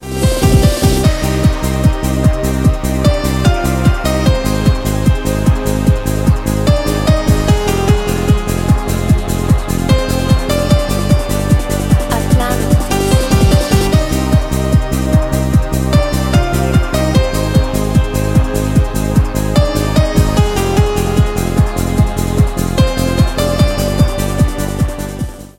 • Качество: 128, Stereo
евродэнс